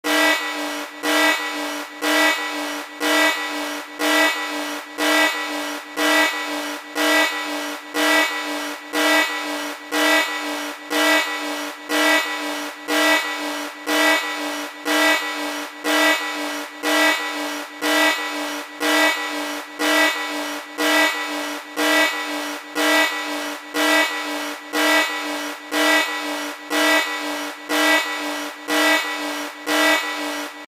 Звуки сирены